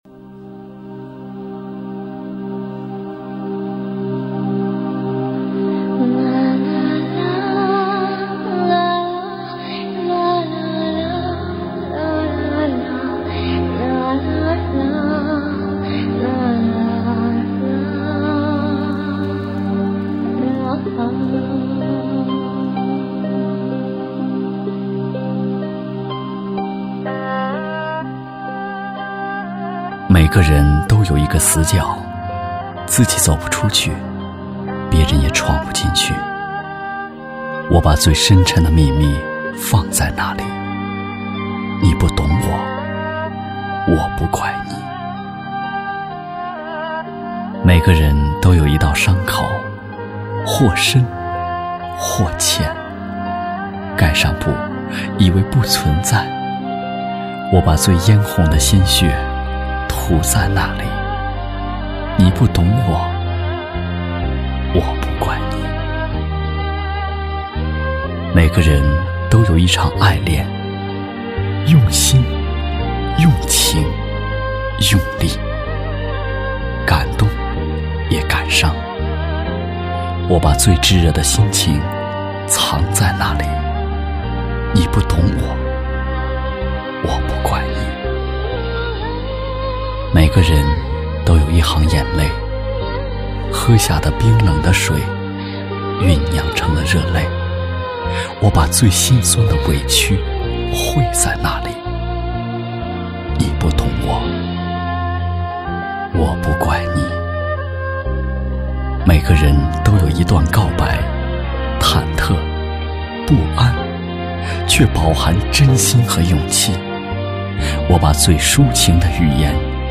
朗诵-【美文】《你不懂我、我不怪你》(深情版)--声音作品--海滨声音艺术学院